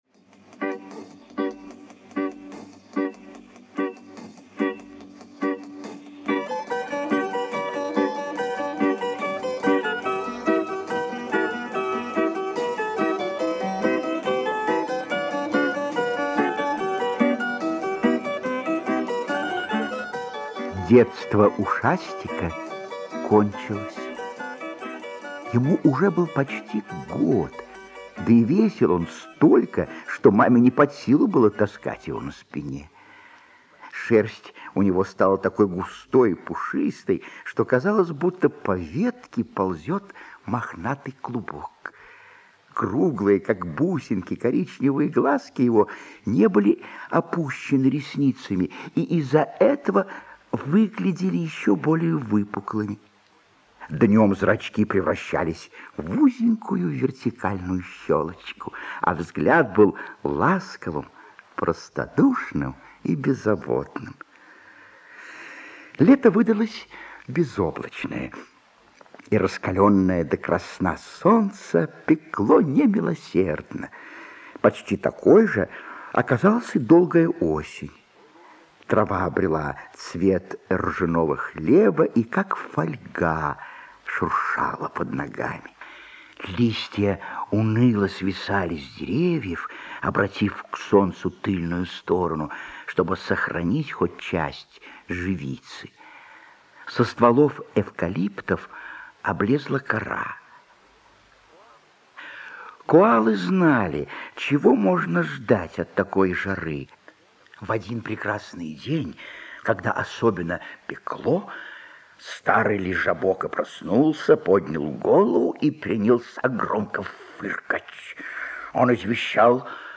Про коалу Ушастика - аудиосказка Лесли Риис